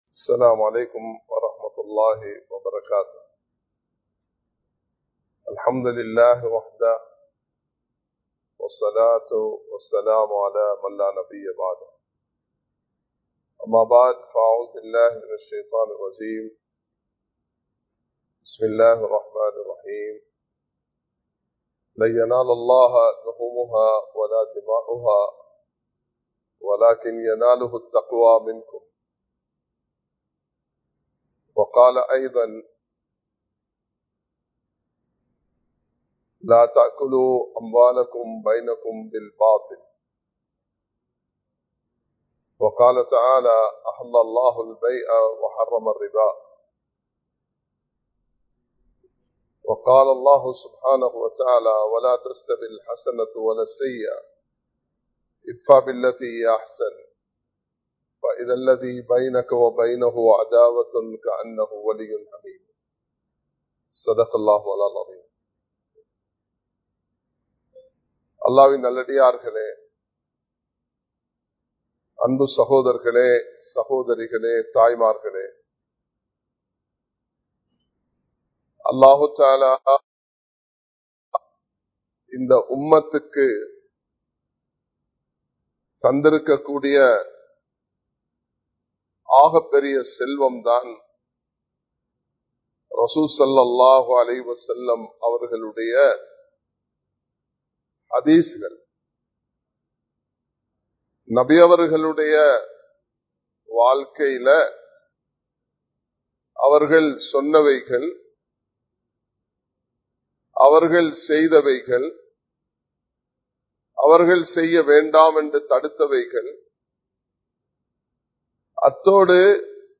முன் மாதிரியான முஸ்லிமாக வாழுங்கள் | Audio Bayans | All Ceylon Muslim Youth Community | Addalaichenai
Live Stream